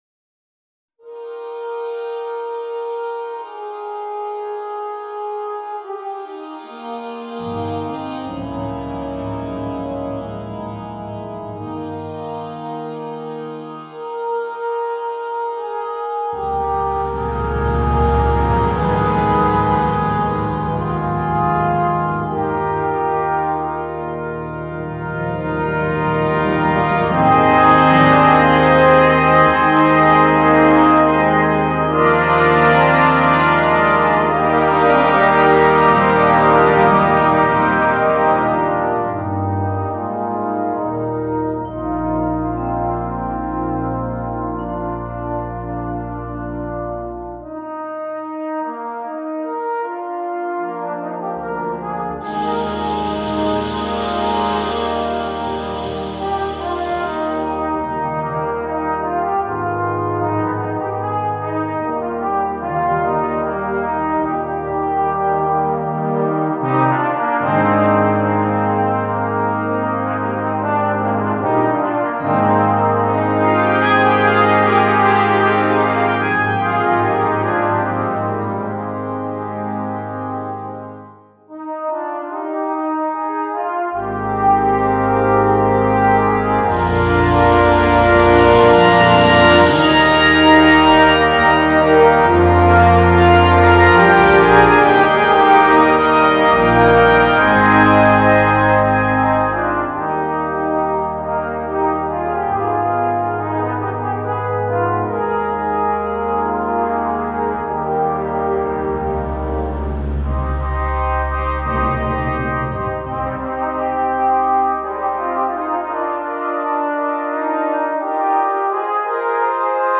2. Brass Band
avec instrument soliste
Cor ténor mib (Duo), Bugle & Cor ténor mib (Duo)
Musique légère